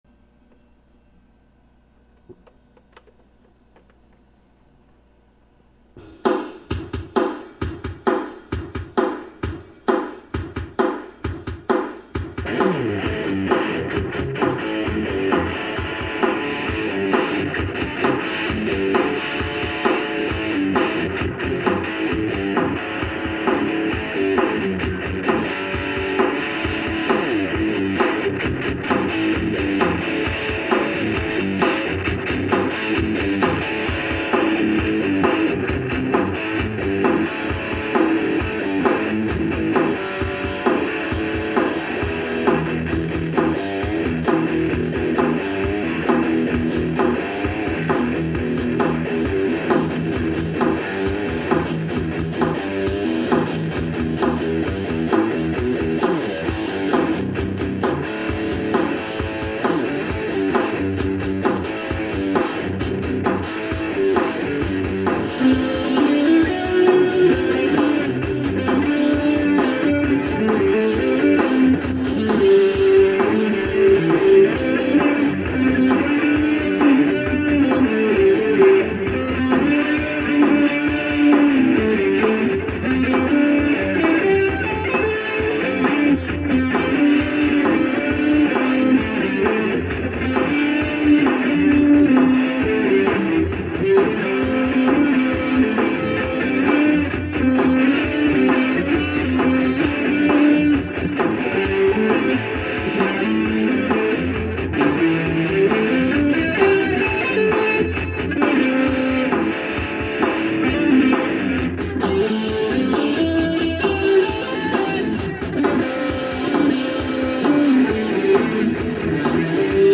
<>+--+<>+RANDOM Lo-Fi+<>+--+<>music
When Johnny Comes Marching IN *GUITAR ONLY* Song ONE home